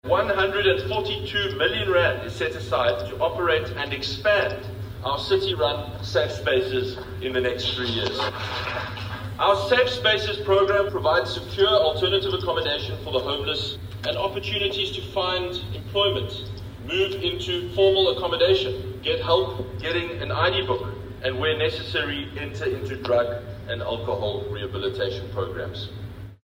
Hill-Lewis, during his first budget speech this week, said he believes Cape Town must become a more caring city that does more to protect the vulnerable.